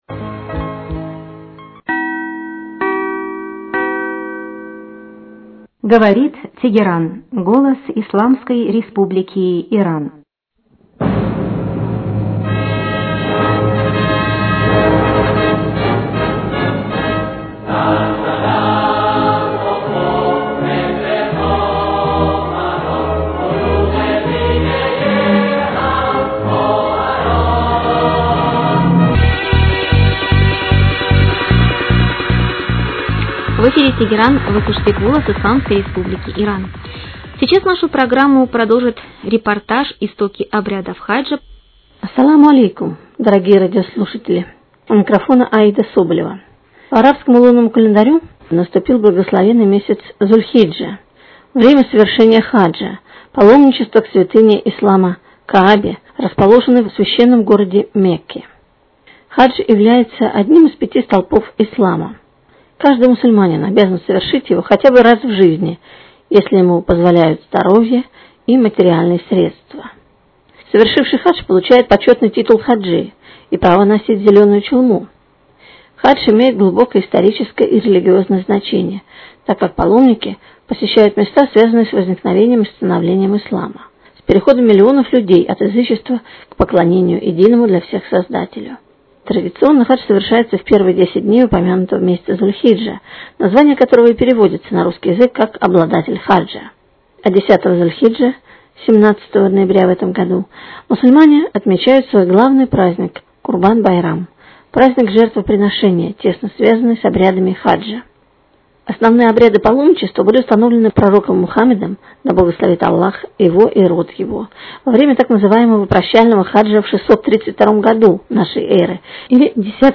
Радио «Голос Исламской Республики Иран», русское вещание, к хаджу 2010 года приурочило очень интересный материал о происхождении обычая поклонения Каабе.